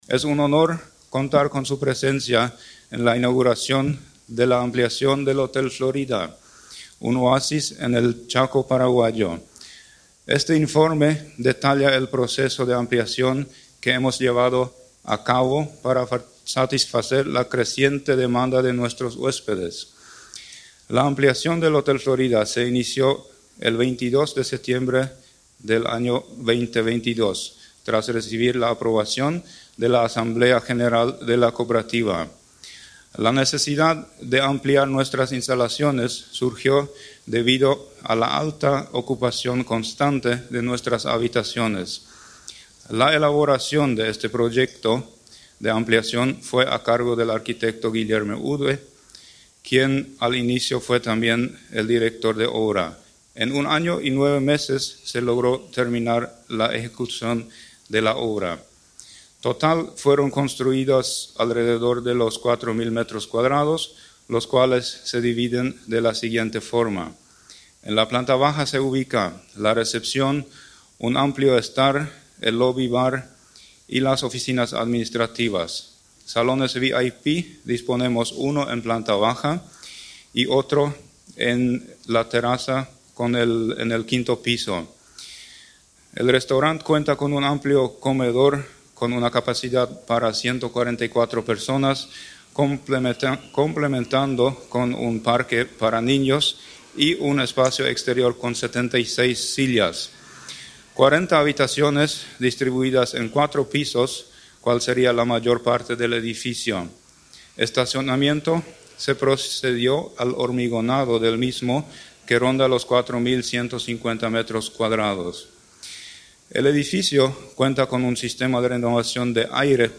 Entrevistas / Matinal 610 Inauguración del Hotel Florida Sep 10 2024 | 00:12:17 1x 00:00 / 00:12:17 Subscribe Share RSS Feed Share Link Embed